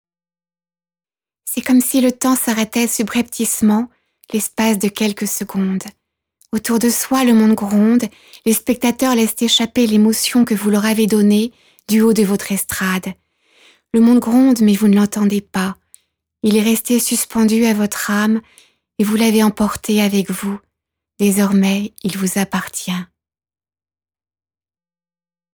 Voix Pub: Divers